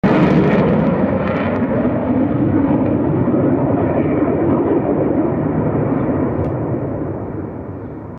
MiG-29 Serbian Air-Force.